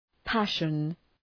Προφορά
{‘pæʃən}